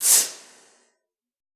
Index of /90_sSampleCDs/Best Service - Extended Classical Choir/Partition I/CONSONANTS